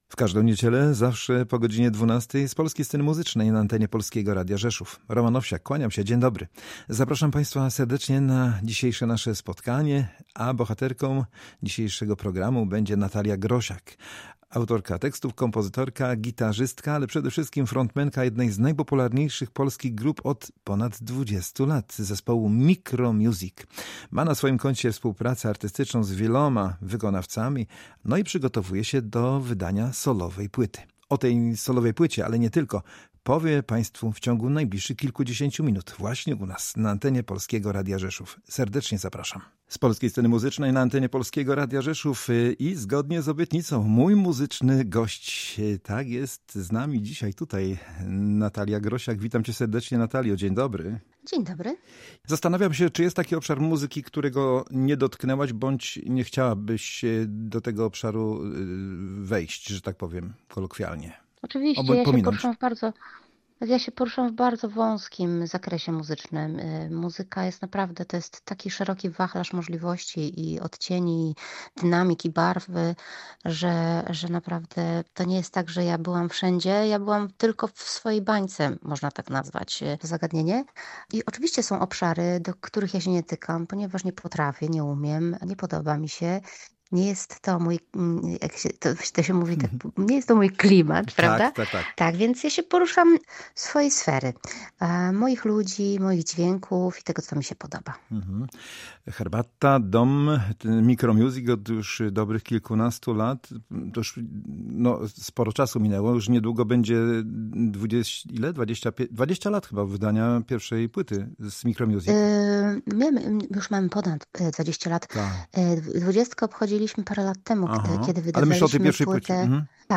Grosiak_wywiad.mp3